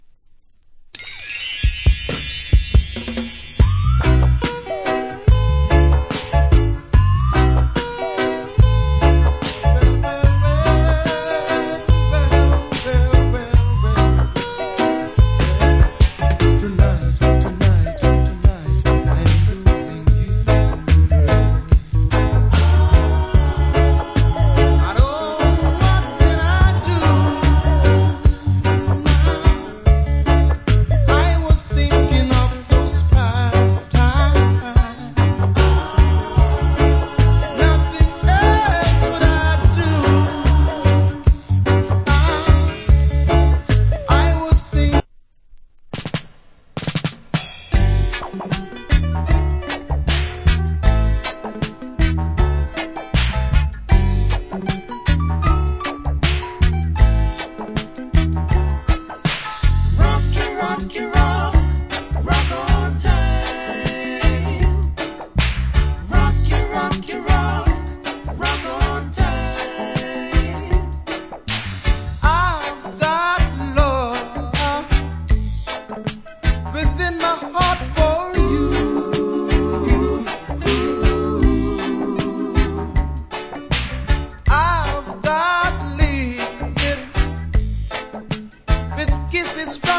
80's Nice Reggae Vocal. Self Remake.